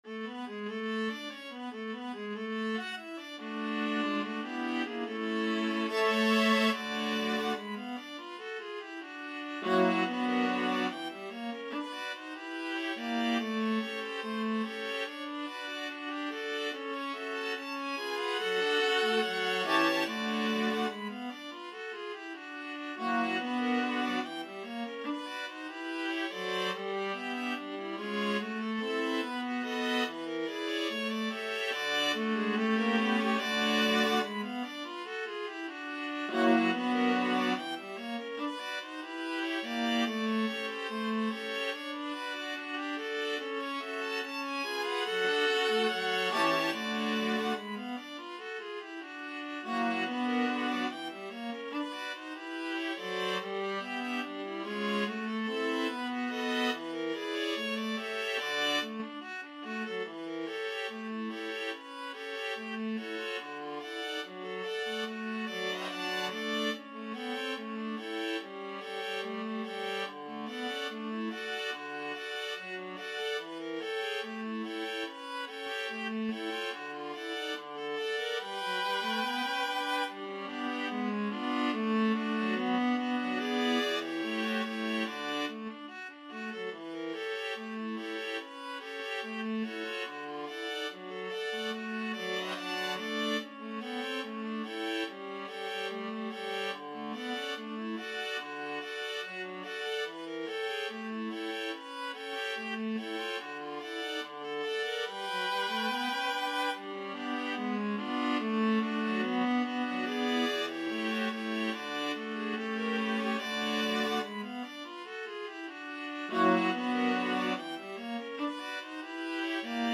2/4 (View more 2/4 Music)
Slow march tempo = 72
Jazz (View more Jazz Viola Ensemble Music)